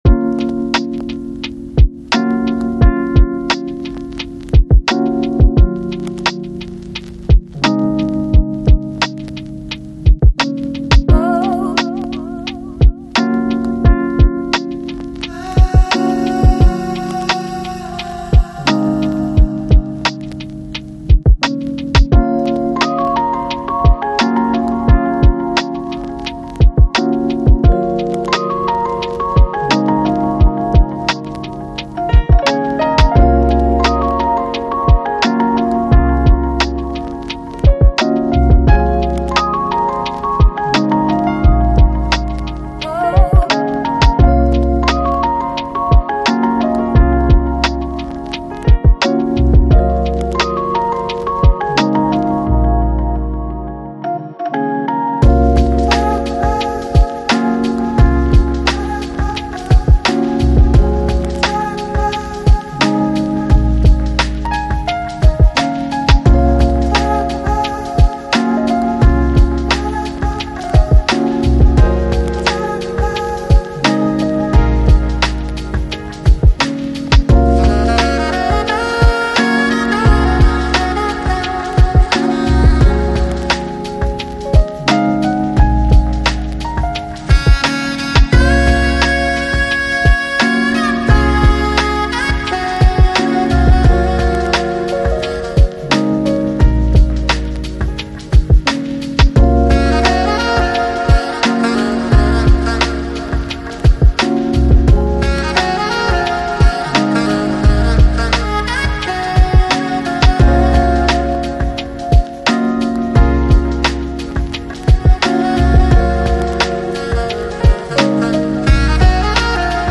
AAC Жанр: Chillout, Lounge, Trip-hop Продолжительность